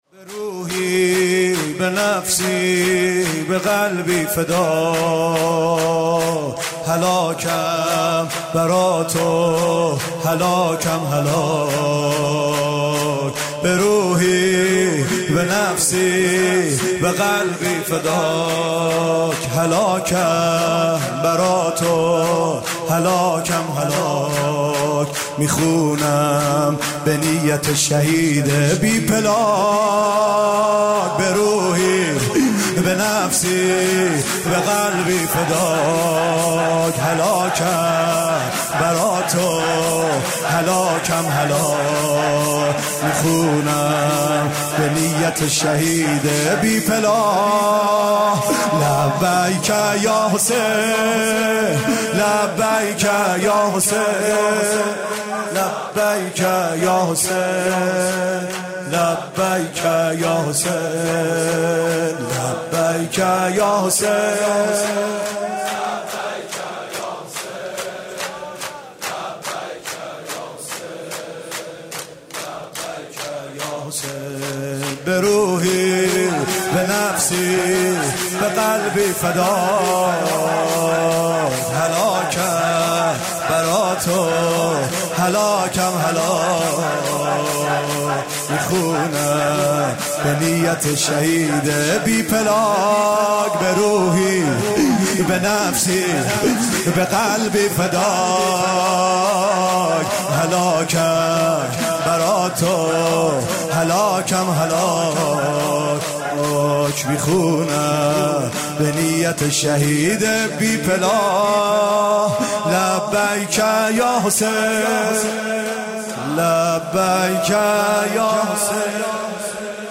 شهادت امام صادق علیه‌السلام
music-icon شور: بروحی، بنفسی، بقلبی فداک